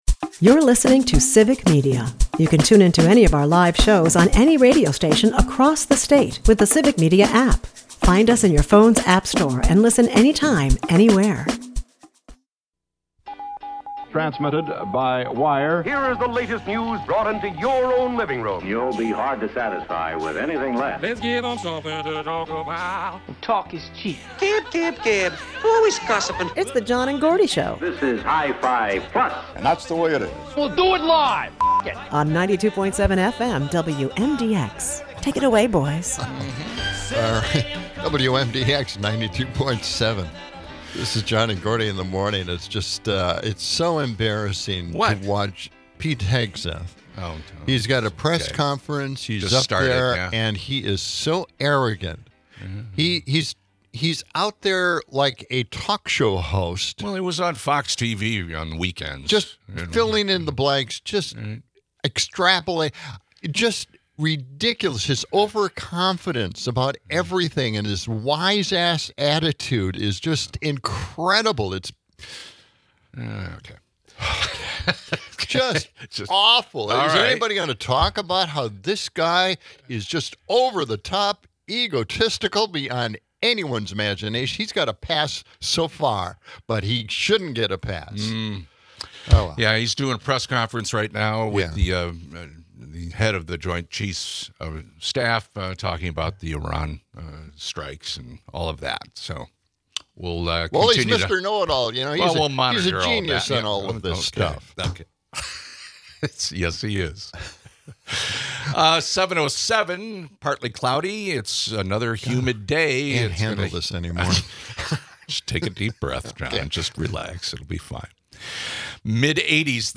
Legal expert